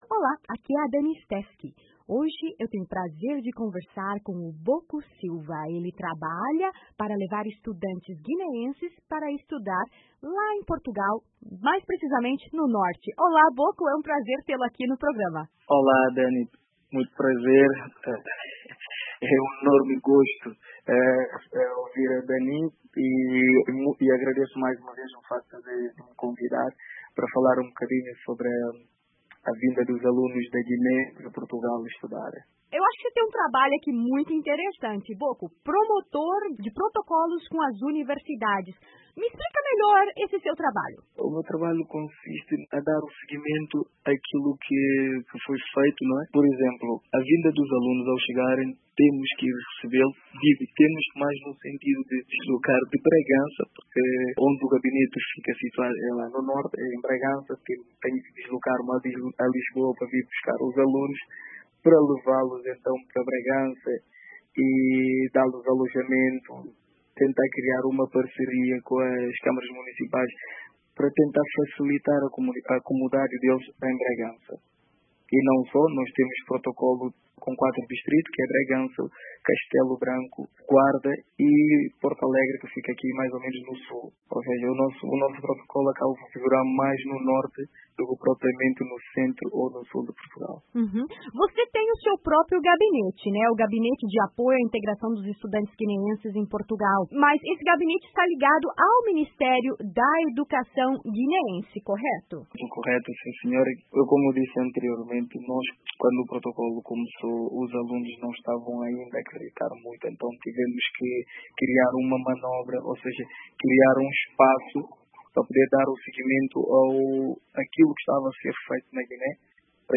Entrevista de áudio